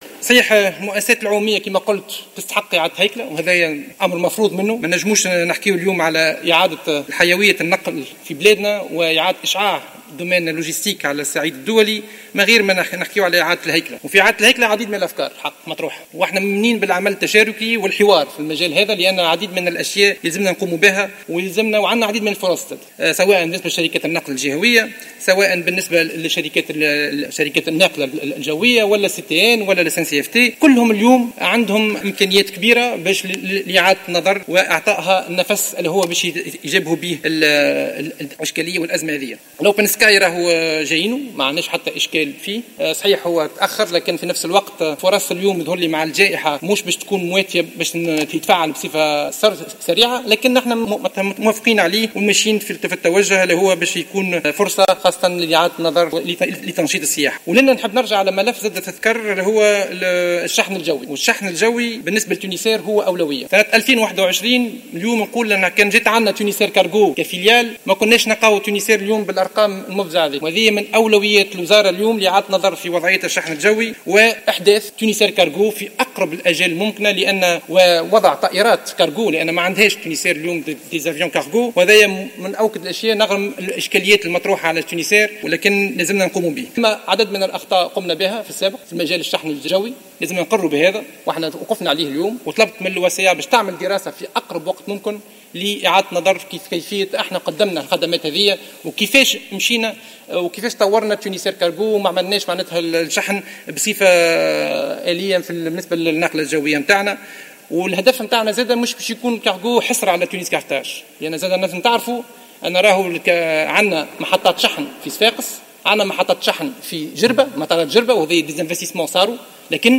وقال شقشوق في اجابته على اسئلة عدد من نواب الشعب خلال مناقشة مهمة وزارة النقل واللوجسيتك لسنة 2021، إنه من الضروري وضع خطة للخطوط التونسية بمختلف فروعها على غرار الخطوط التونسية الفنية الى جانب تطوير المطارات والخدمات المقدمة للحرفاء.